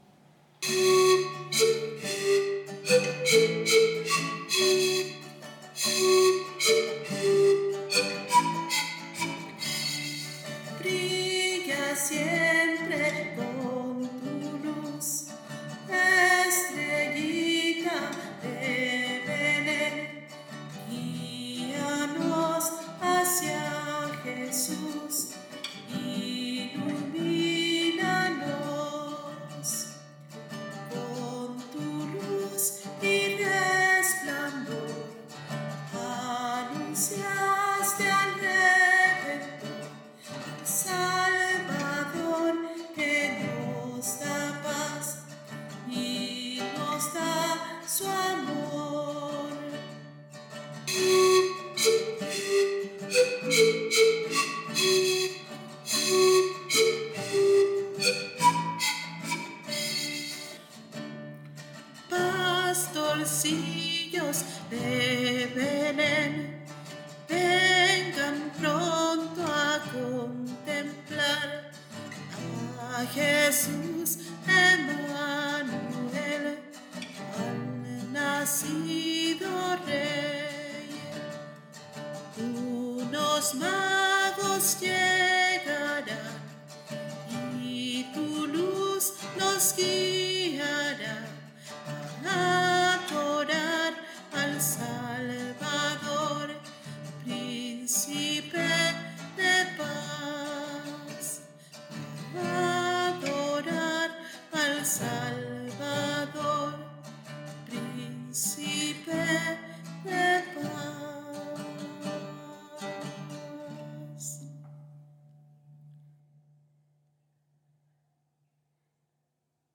Audio con voz: